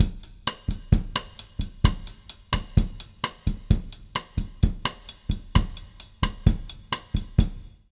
130BOSSA01-R.wav